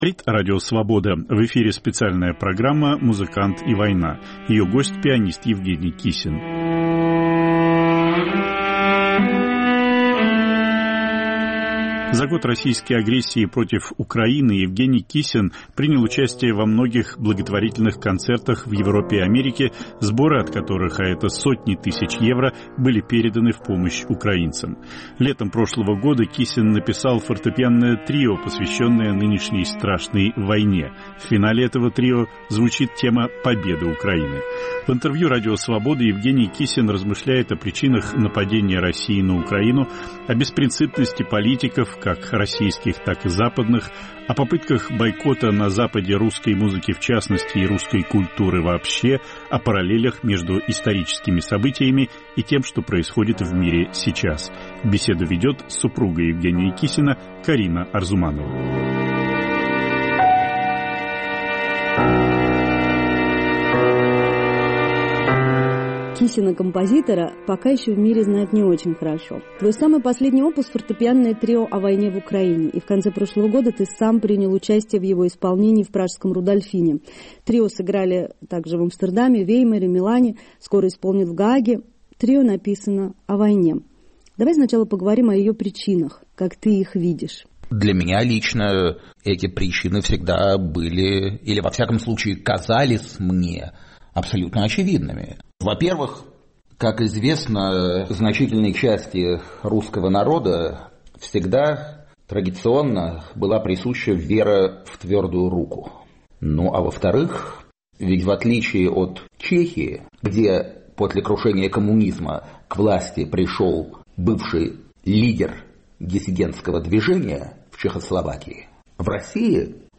Пианист Евгений Кисин размышляет о причинах агрессии России против Украины, о беспринципности политиков, о попытках бойкота русской музыки в частности и русской культуры вообще, о параллелях между историческими событиями. Повтор эфира от 24 февраля 2023 года.